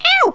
Ow2.wav